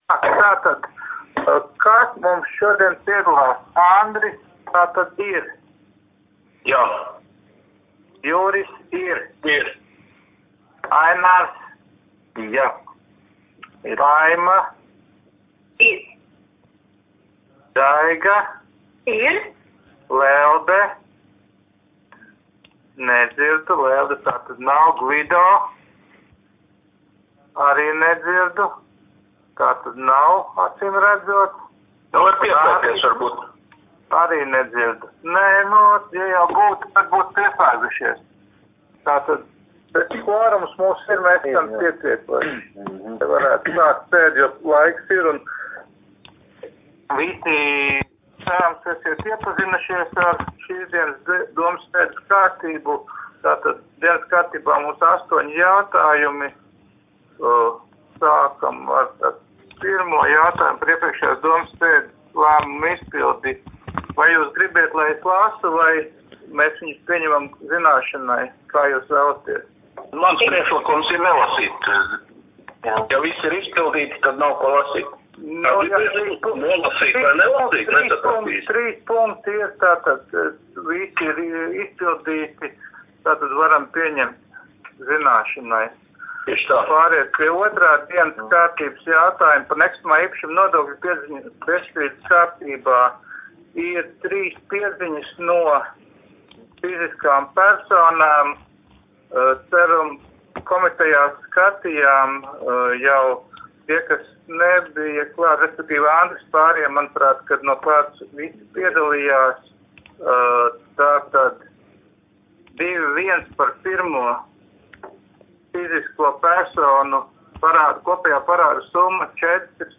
Mērsraga novada domes sēde 19.05.2020.